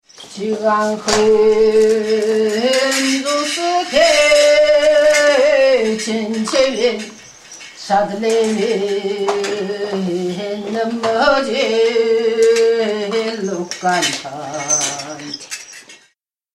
Daur Shaman Ceremonial Chanting
Recorded in Inner Mongolia, China, a female shaman of the Daur ethnicity, is chanting in her native language of Daur, and playing her drum during an empowerment ceremony. This improvised chant is exacting in cadence and offers strength to listeners.